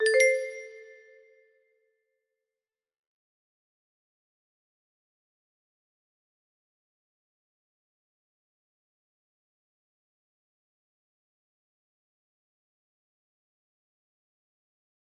BreakEnd music box melody